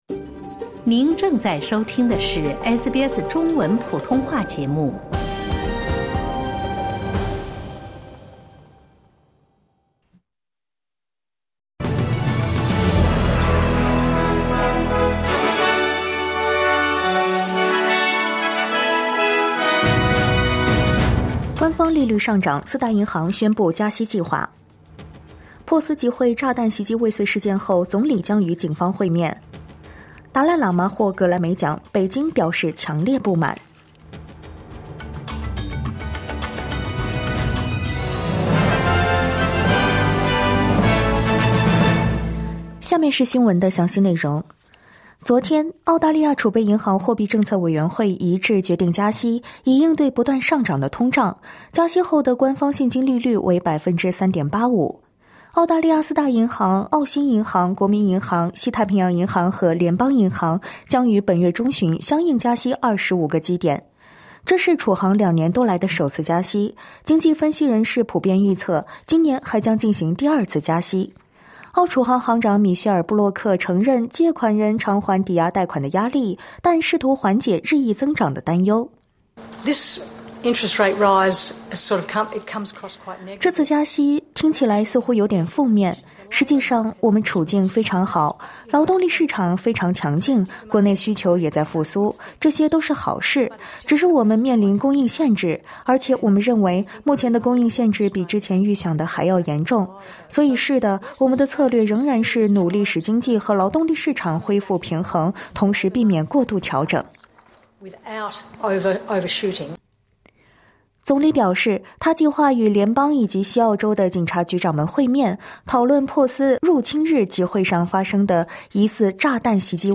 【SBS早新闻】达赖喇嘛获格莱美奖 北京表示强烈不满